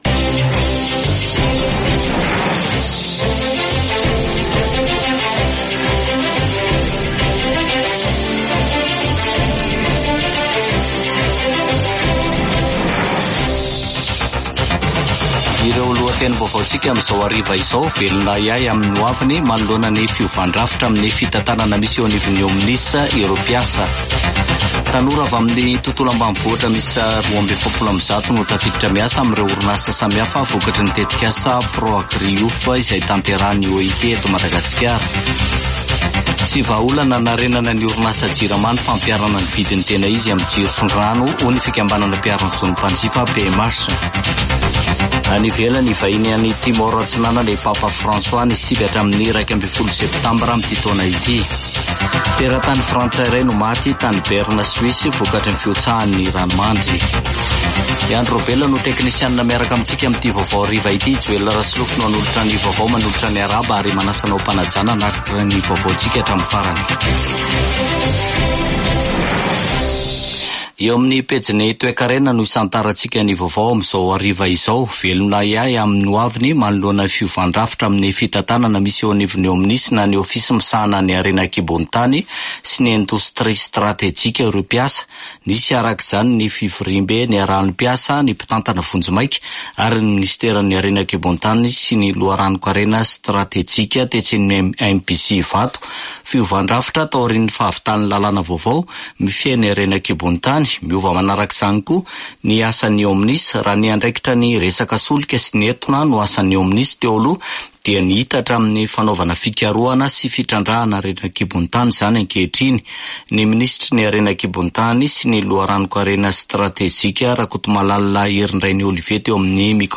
[Vaovao hariva] Zoma 10 mey 2024